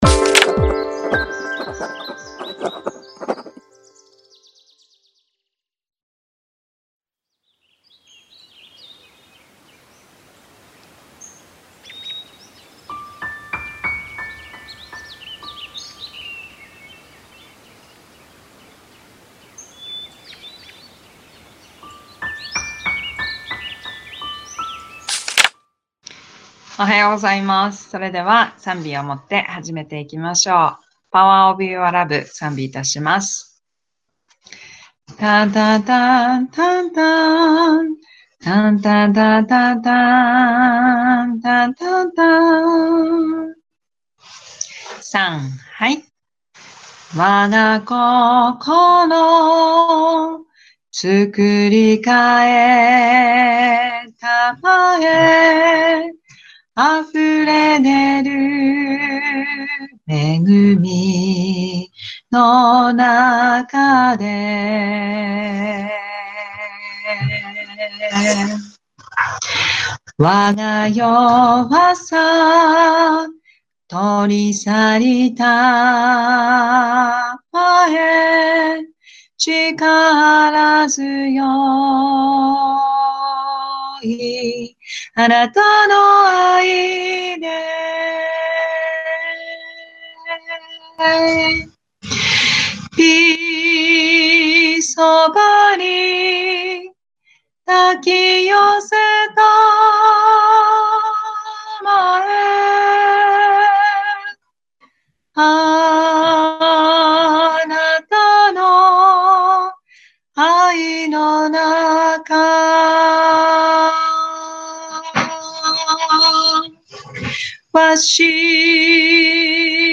音声版（mp3)←←右クリック「名前を付けてリンク先を保存」で保存できます ※映像と音声が一部乱れている部分がございます。